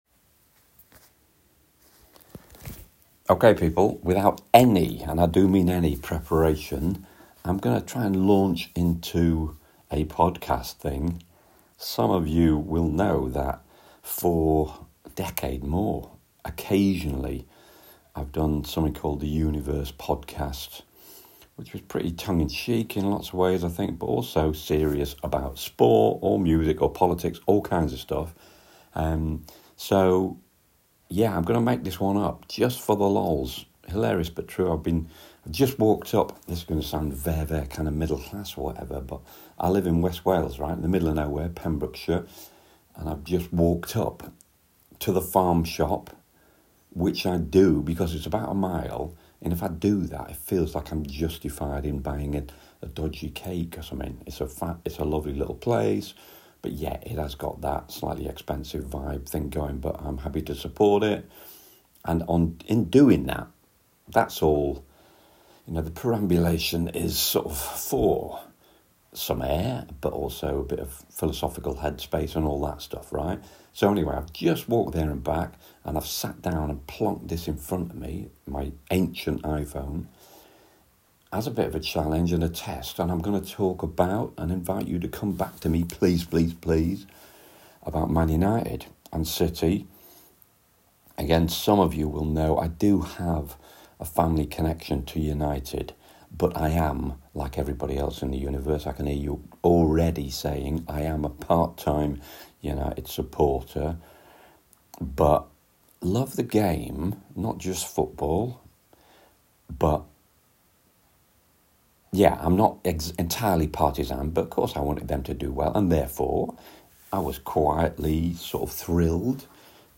Foolishly but characteristically decided to allow myself no notes or prep… then talk United City.